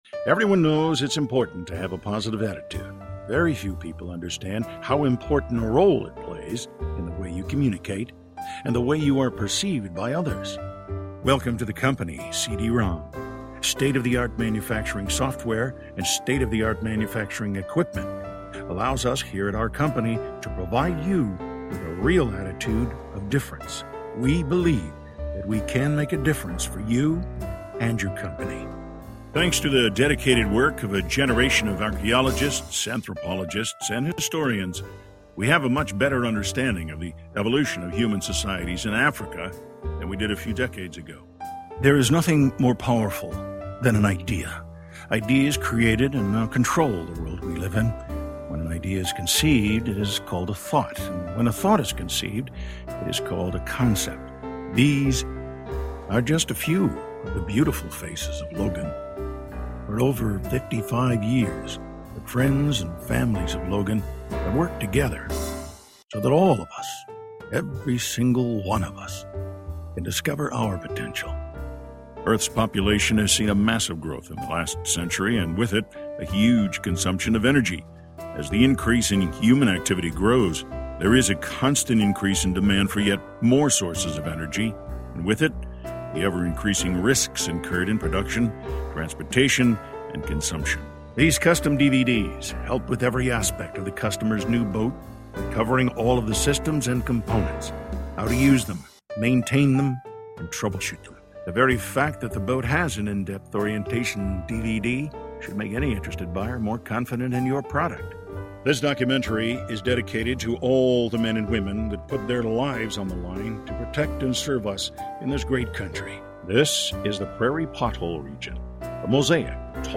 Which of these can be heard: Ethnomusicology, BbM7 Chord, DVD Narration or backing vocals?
DVD Narration